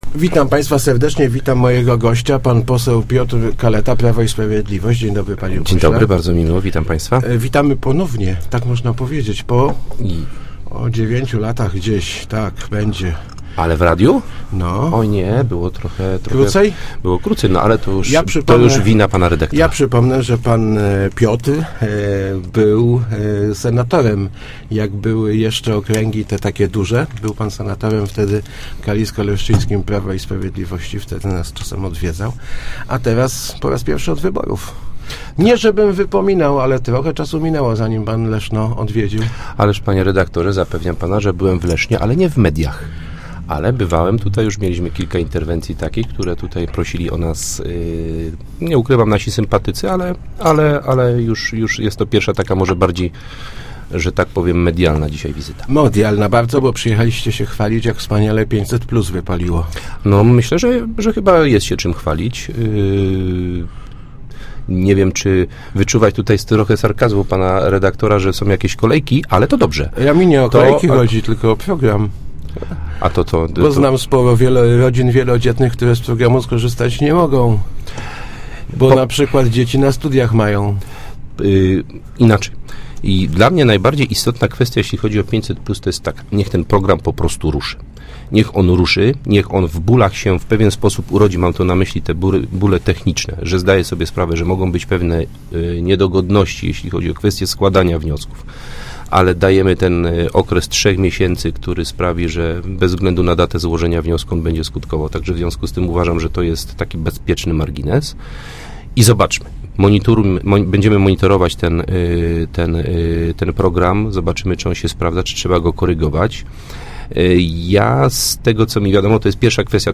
- Zmiana przepisów dotycz�cych aborcji to wynik werdyktu wyborców, którzy na nas zag�osowali - mówi� w Rozmowach Elki pose� PiS Piotr Kaleta. Jego zdaniem proponowane w obywatelskim projekcie zaostrzenie prawa jest niezb�dne.